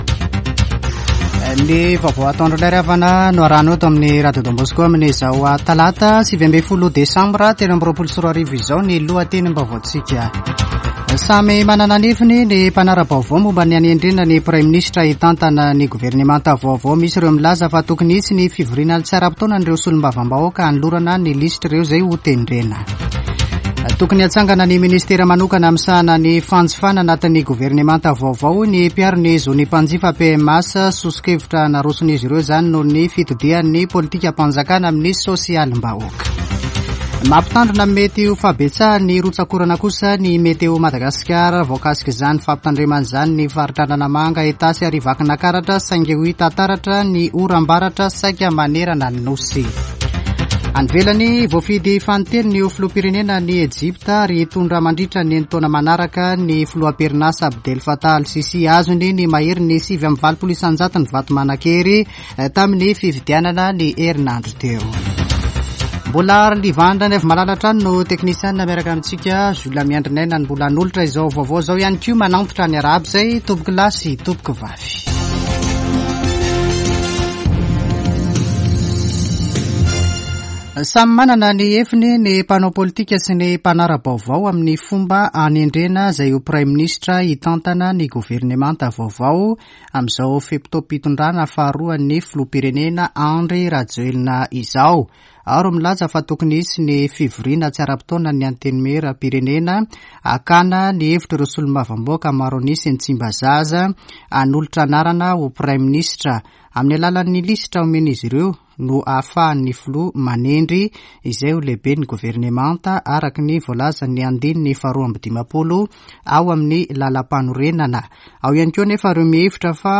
[Vaovao antoandro] Talata 19 desambra 2023